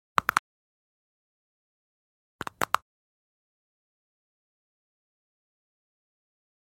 На этой странице собраны реалистичные звуки удара кулаком — от легких хлопков до мощных нокаутирующих ударов.
Хруст пальцев перед ударом кулаком